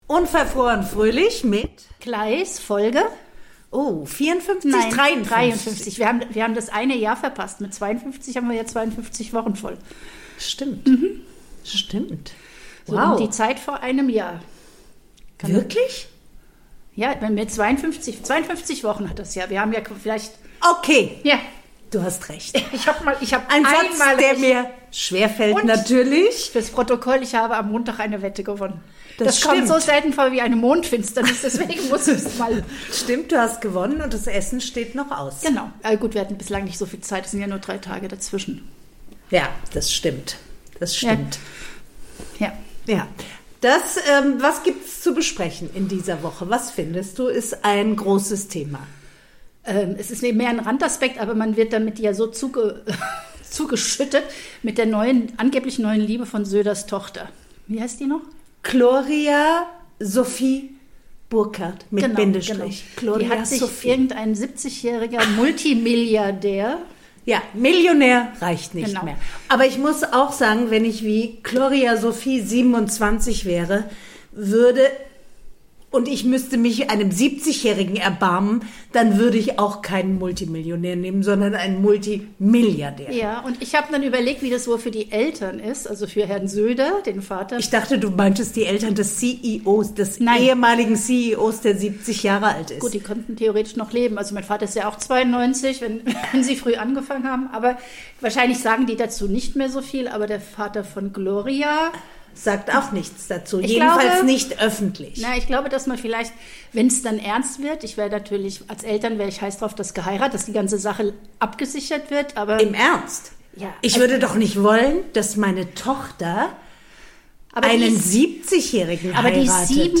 reden die beiden Podcasterinnen über die närrischen Tage, über die junge Liebe zu einem alten Kerl und darüber was uns so gar nicht juckt.